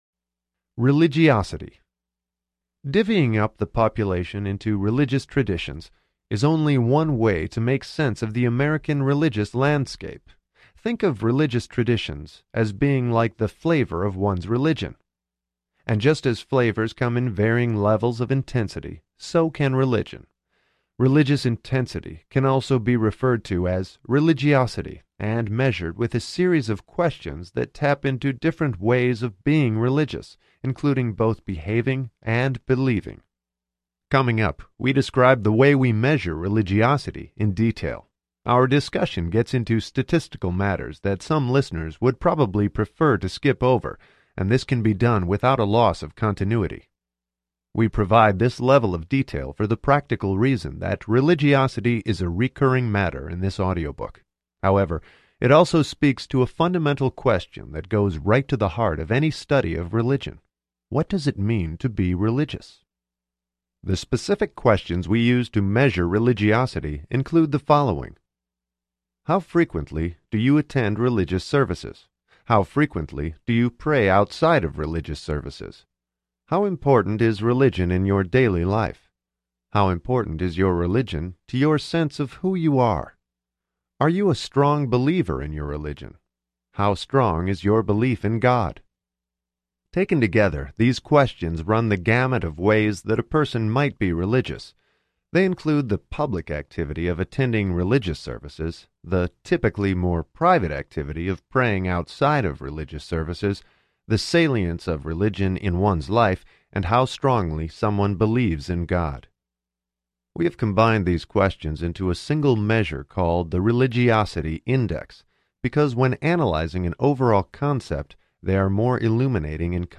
American Grace Audiobook